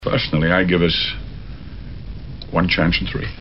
Match the Sean Connery Scottish drawl to the movie in which he utters the line.